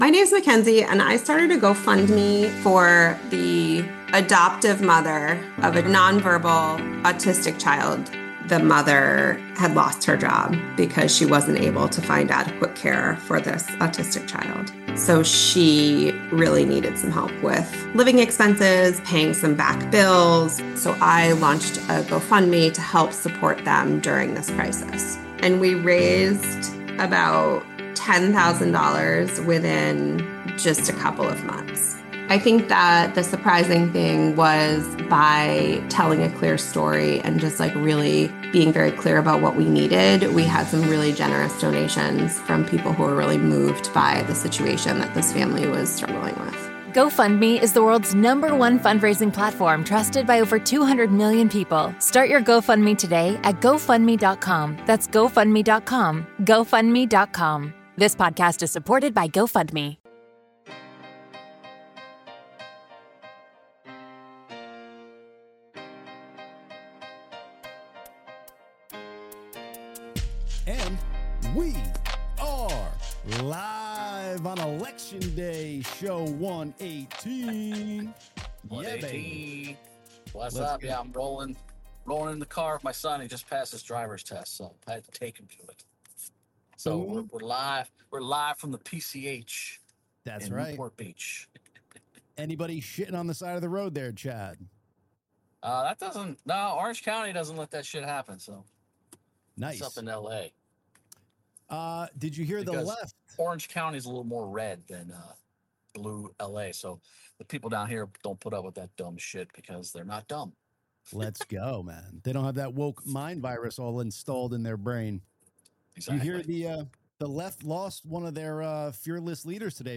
The Gracious Two LIVE Podcast - Every Tuesday at 1 pm EASTERN...